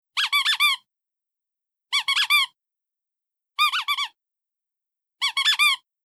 Squeaky Toy
Squeaky Toy 2.m4a